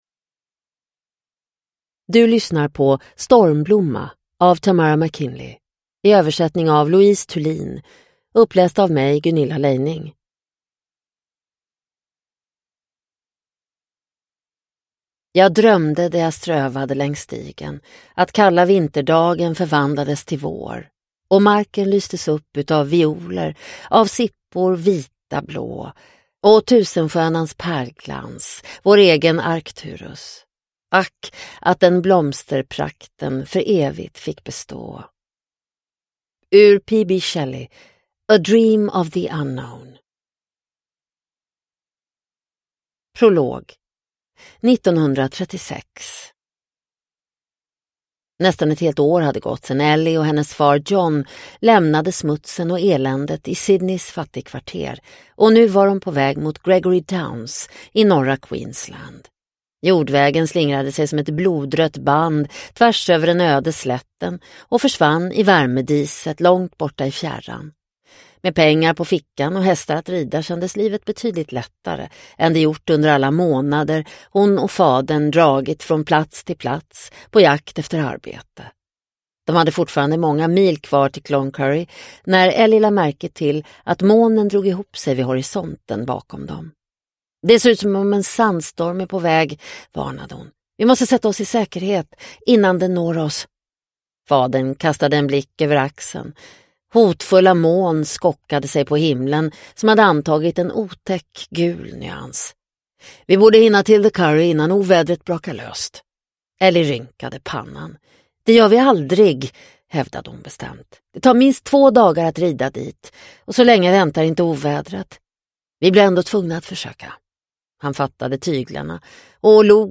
Stormblomma – Ljudbok – Laddas ner